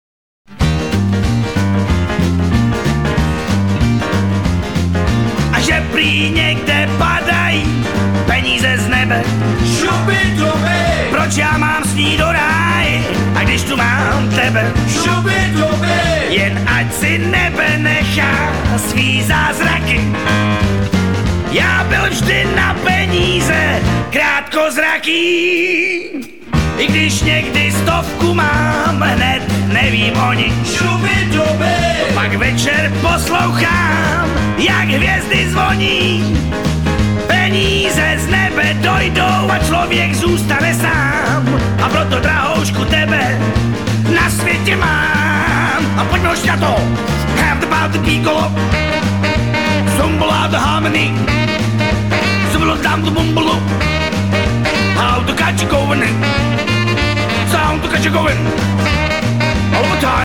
Rock’n’Roll!